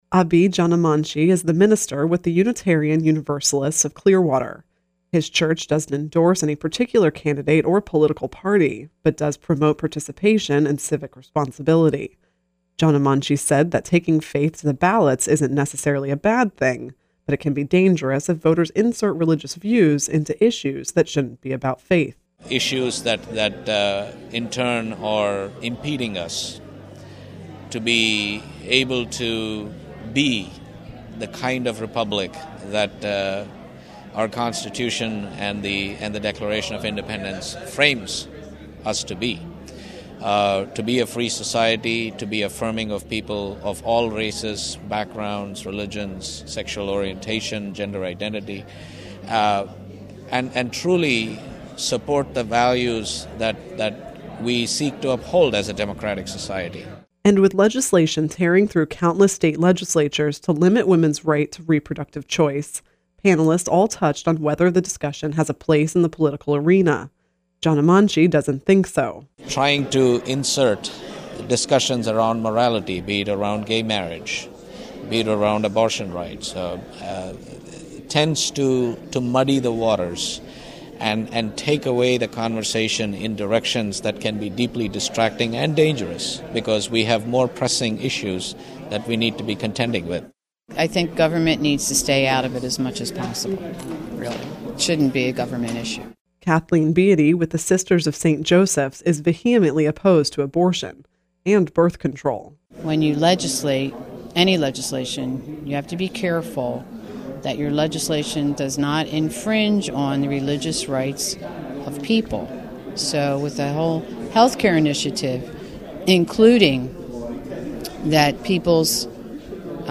At a panel discussion at St. Petersburg College in Seminole this month, each member expressed devout religious views, but none supported basing political decisions on theological beliefs...